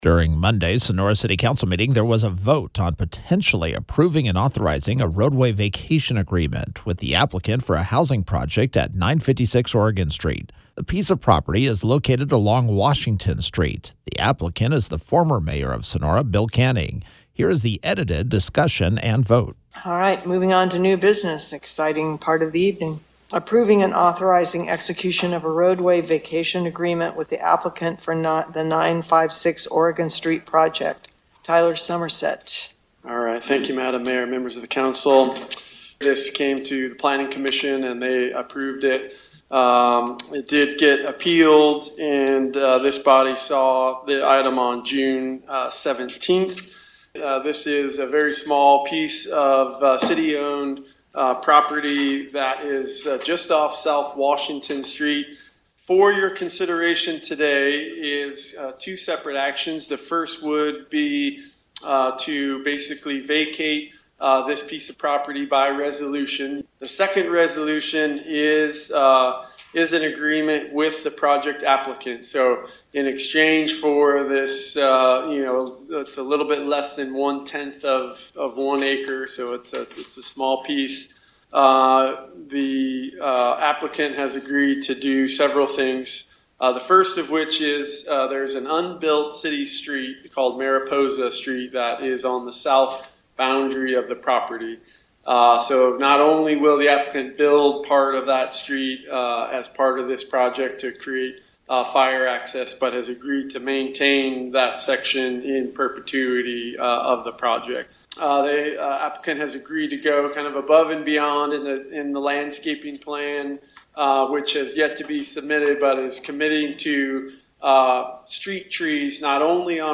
The lively discussion was featured as Friday’s KVML “Newsmaker of the Day”.